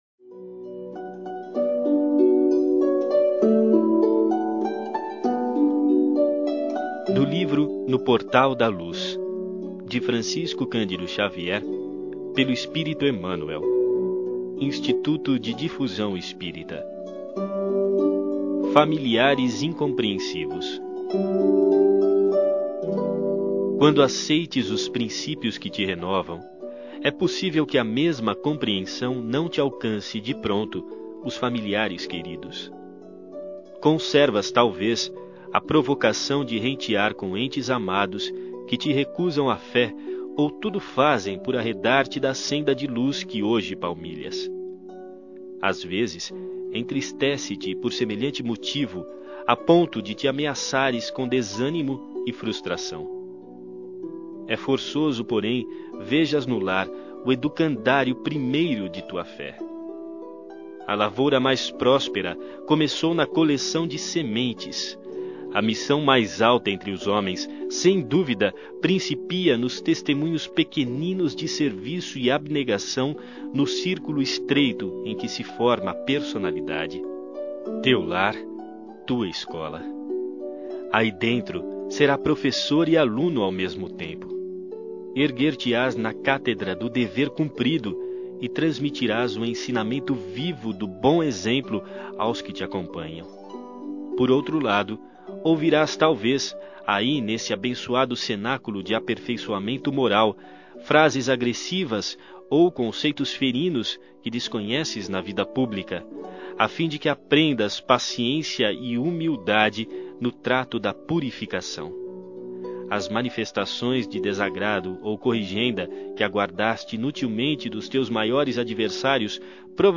Ouça outras mensagens na voz de Chico Xavier Clicando aqui